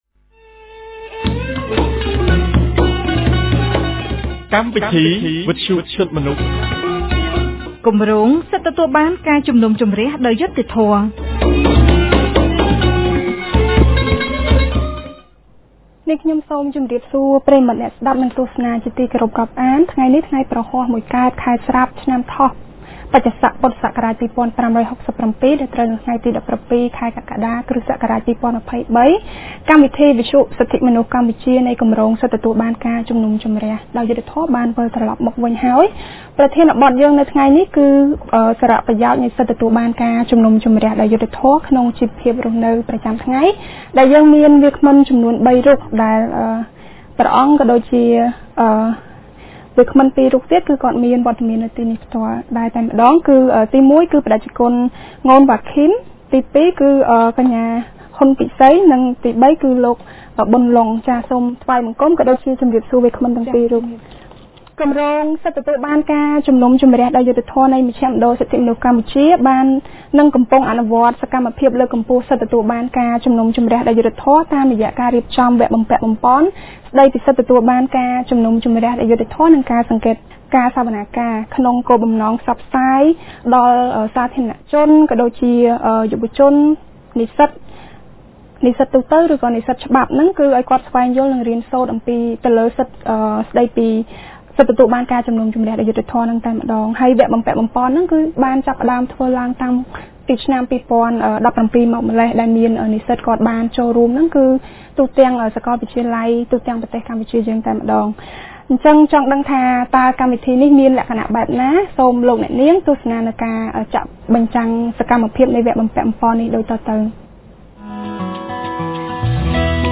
On Thursday 17, August 2023, CCHR’s Fair Trial Rights Project (FTRP) of Cambodia center for human rights held a radio program with a topic on the Advantages of the fair trial rights in daily life.